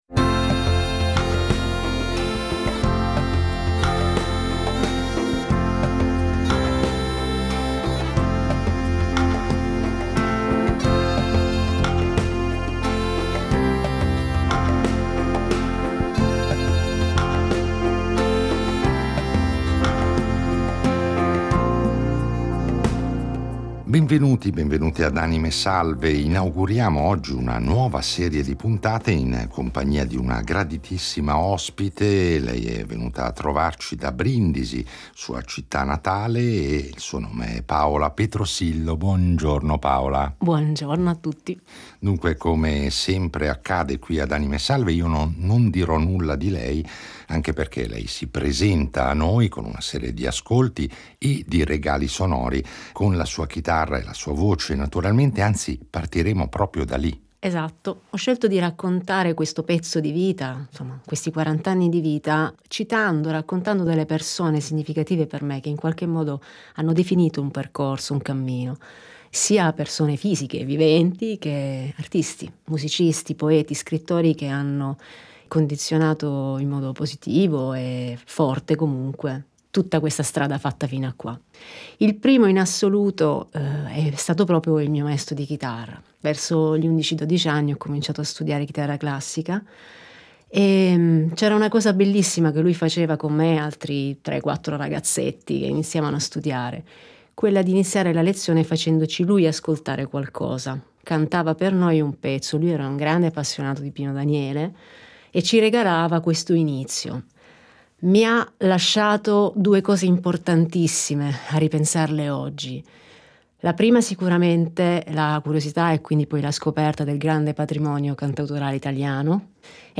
Accompagnandoci nel suo itinerario sonoro ci parlerà delle sue passioni, della sua intensa attività didattica, delle sue esperienze più significative con particolare riferimento alla più recente dedicata ai bambini, “ La carovana delle merende ”, e naturalmente ci offrirà con la chitarra degli esempi dal vivo della sua musica e della sua poetica.